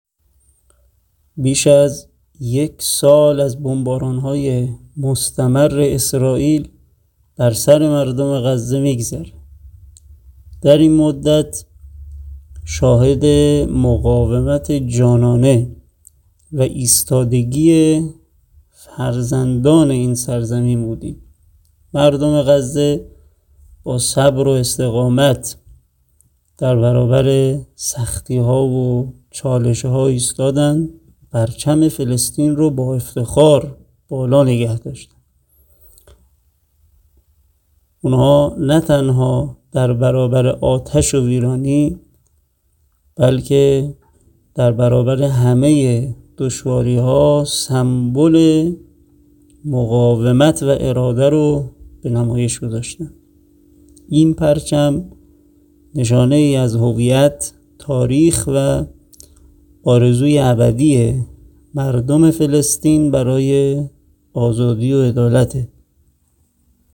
سخنرانی کوتاه| ایستادگی مردم غزه در برابر بمباران‌ها